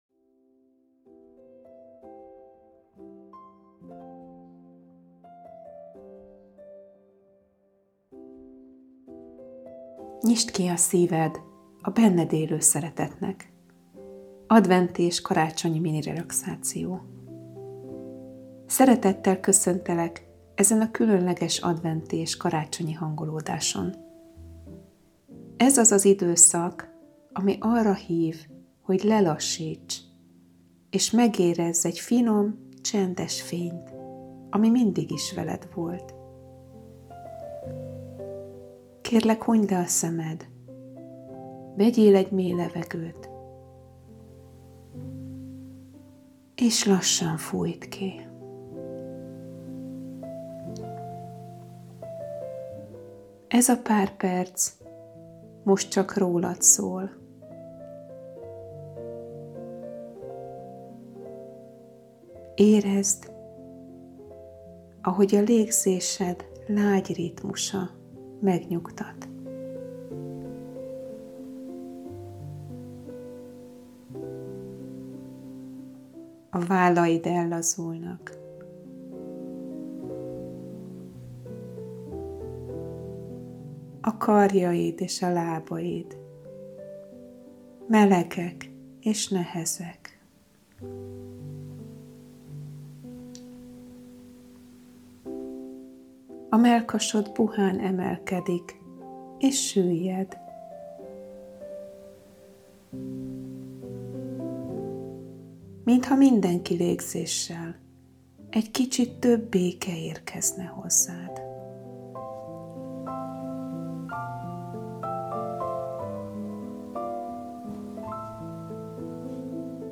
Személyre szabott vezetett relaxáció
Személyre szabott felvételeim az autogén tréninget ötvözik intuitív iránymutatással, a megajándékozott igényeihez igazítva.
A szövegen túl a hangom is energiát közvetít, ami segít a blokkok feloldásában, egyensúly helyreállításában és a mély relaxáció elérésében.